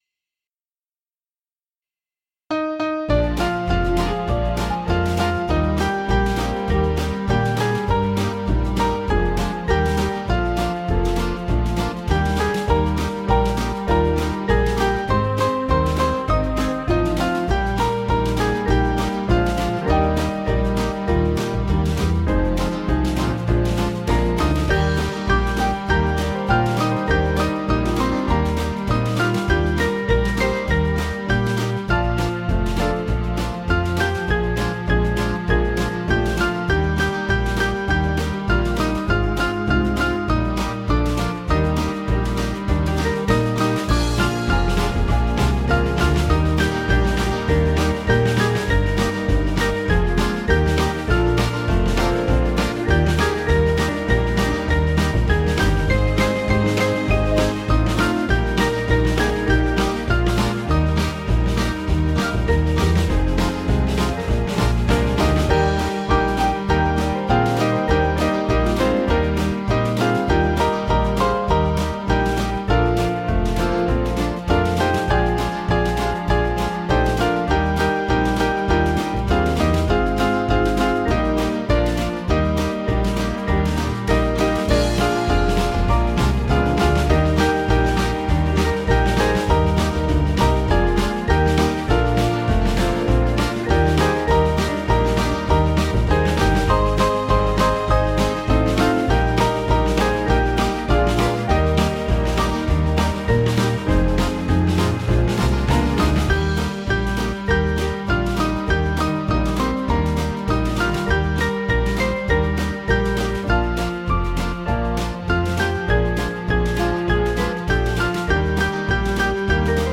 Small Band
(CM)   4/Eb 487.8kb